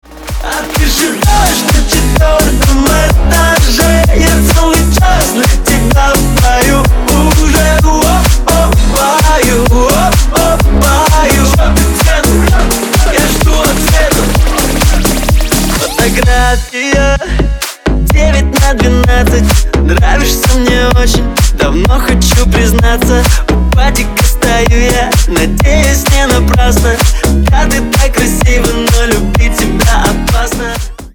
Ремикс
клубные # весёлые